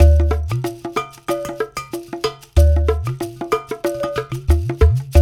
93 -UDU 02L.wav